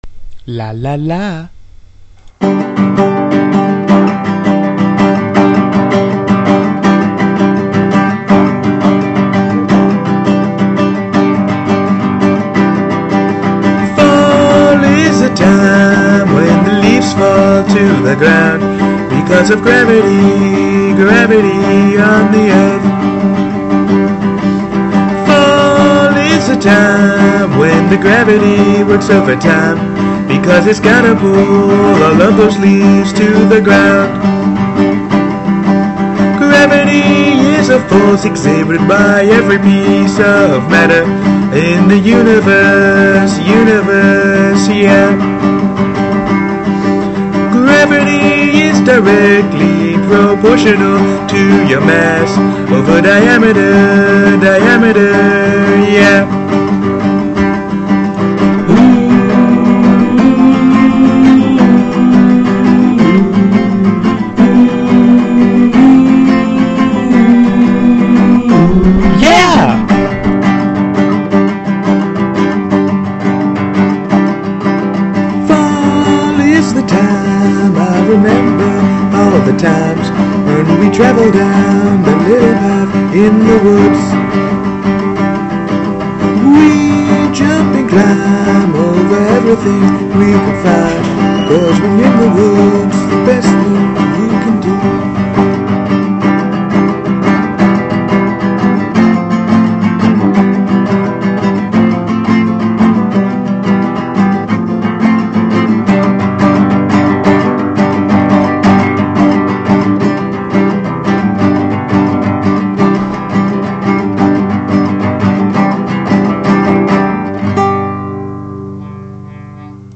Quality: kinda bad